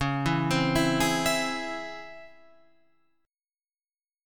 C#mM7 chord